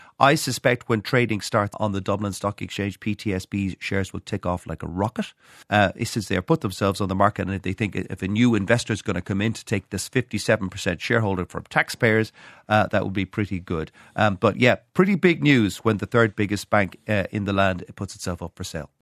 Business Editor